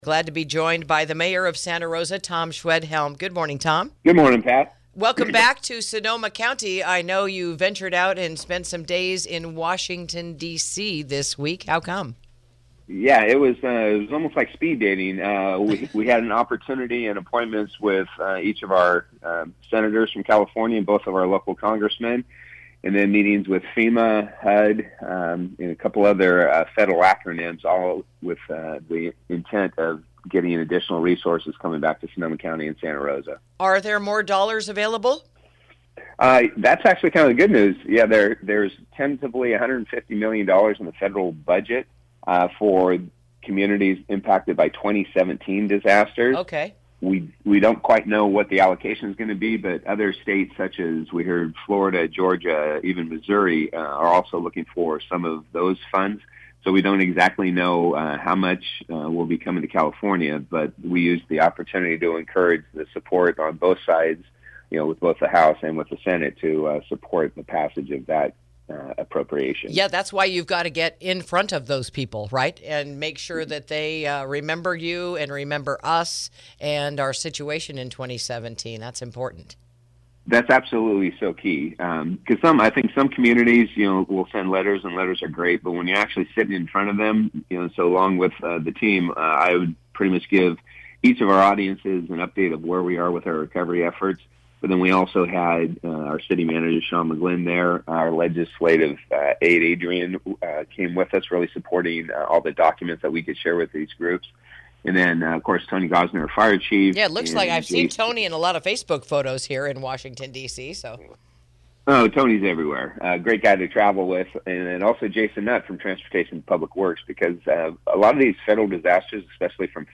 INTERVIEW: Santa Rosa Mayor Schwedhelm Recaps His Recent Trip to Washington to Gain Support for Recovery Funding